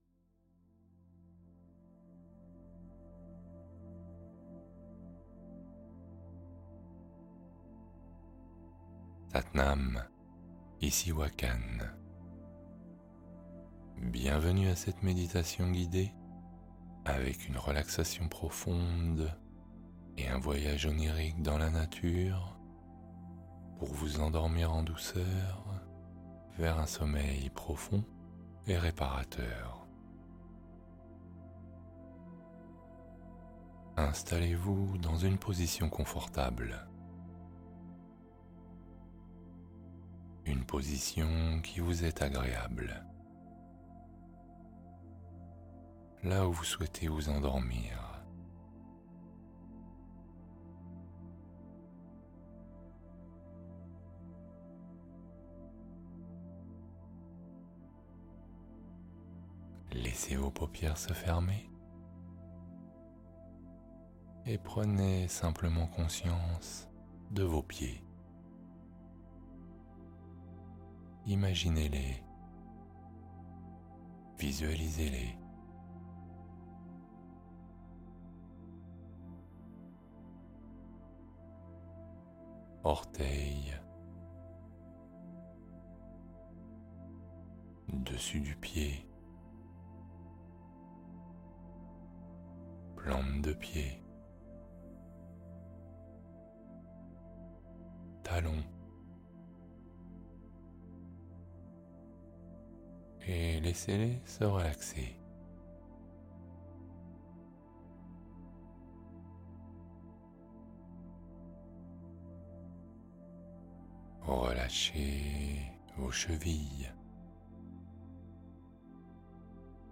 Endormez-vous en 20 minutes : méditation guidée pour un sommeil profond instantané